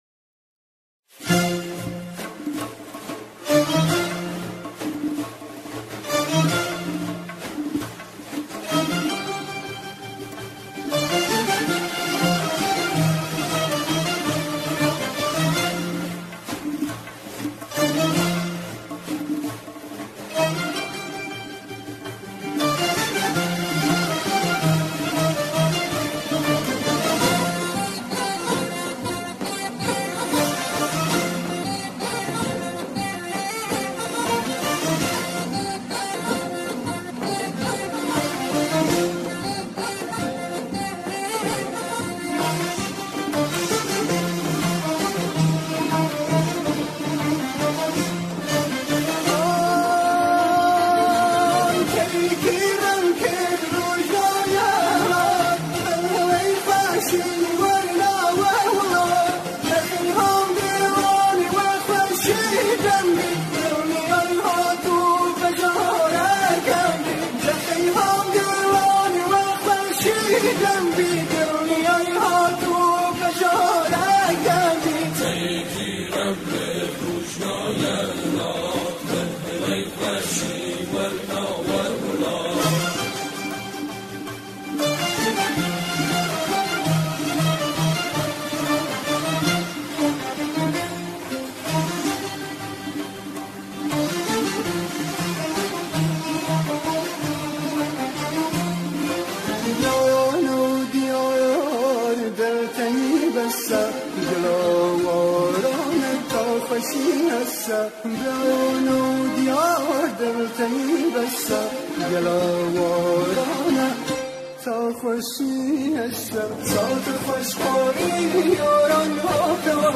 همراهی گروه همخوان اجرا شده است.